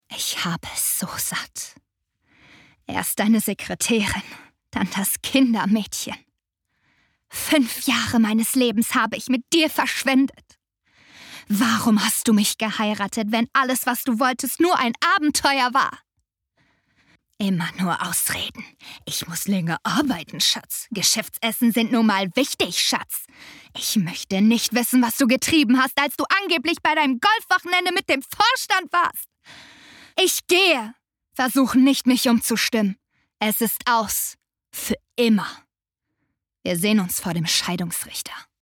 Verletzt, wütend und zutiefst enttäuscht, stellt sie ihren Mann zur Rede.